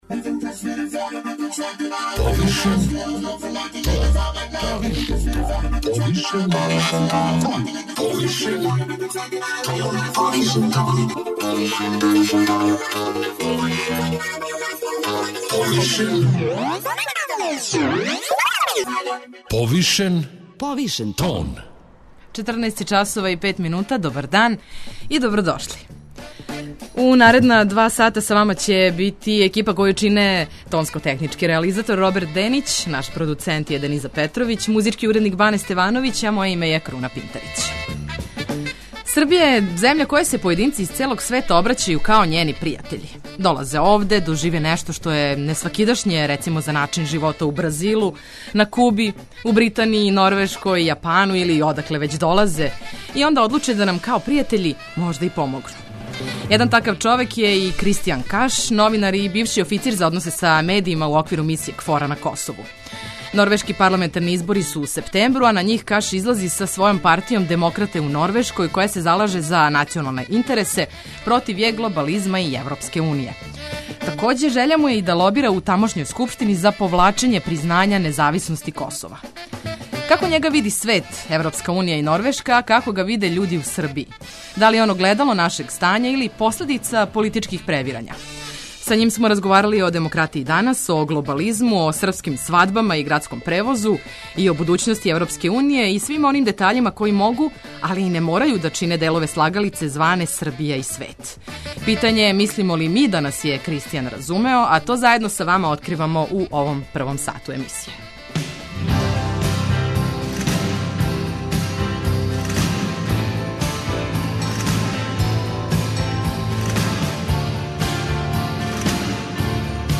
преузми : 51.94 MB Повишен тон Autor: Београд 202 Од понедељка до четвртка отварамо теме које нас муче и боле, оне о којима избегавамо да разговарамо aли и оне о којима разговарамо повишеним тоном.